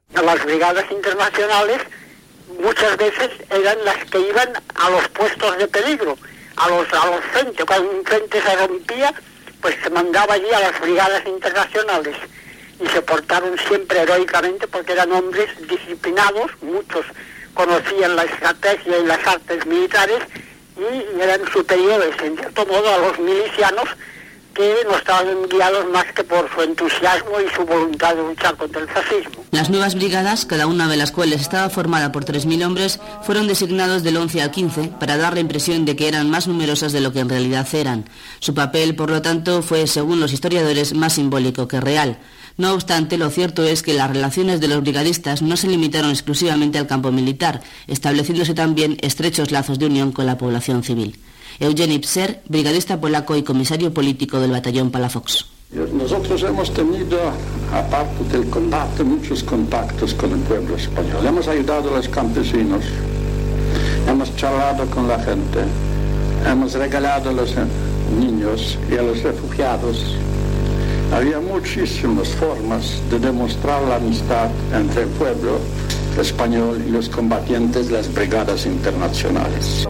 Reportatge dels cinquanta anys de la creació de les Brigades Internacionals.
Paraules de Federica Montseny, la primera dona ministra a l'època de la República espanyola, i records d'un brigadista.
Informatiu